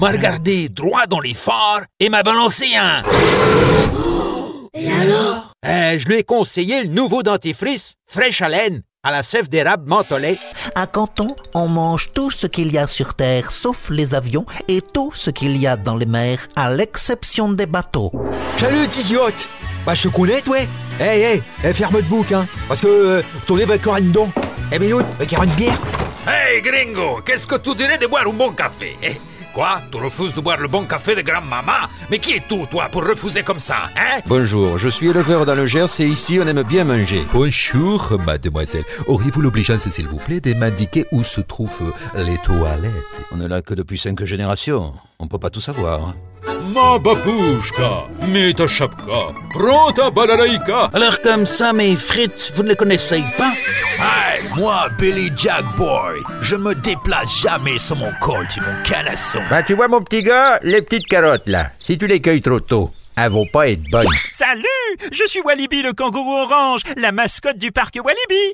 Voix caméléon 3 octaves.
Comédien Français, voix grave médium caméléon.
Sprechprobe: Sonstiges (Muttersprache):